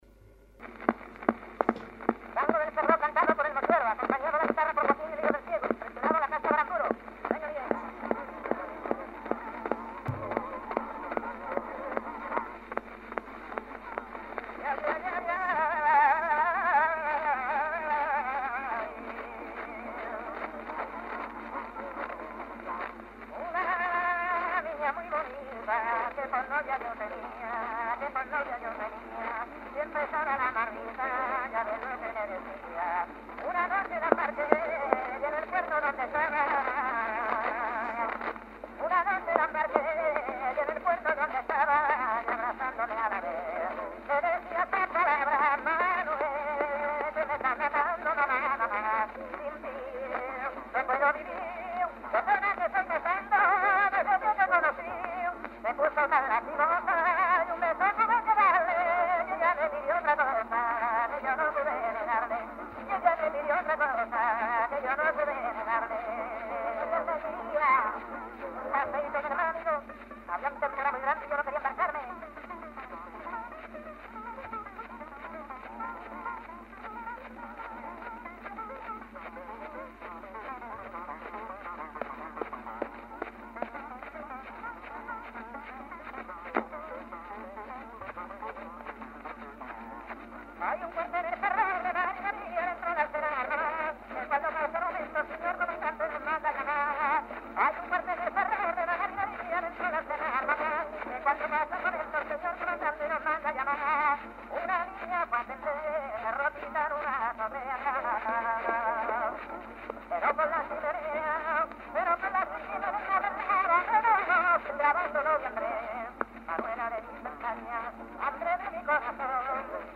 acompañamiento de guitarra
tango